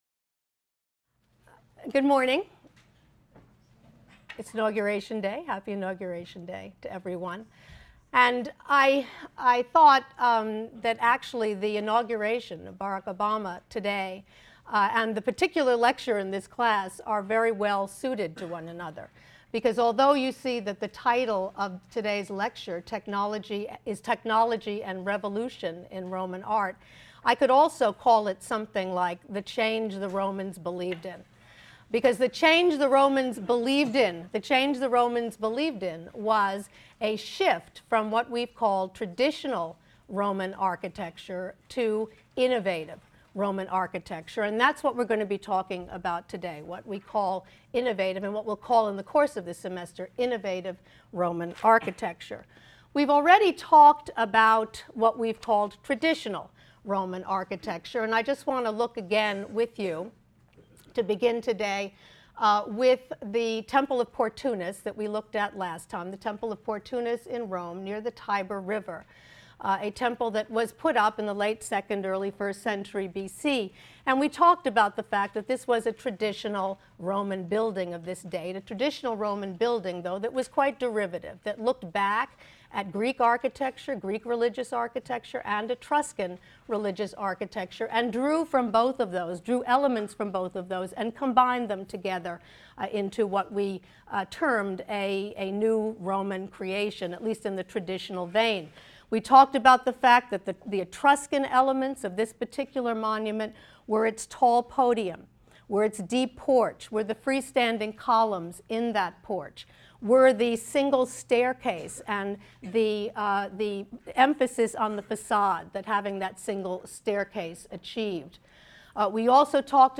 HSAR 252 - Lecture 3 - Technology and Revolution in Roman Architecture | Open Yale Courses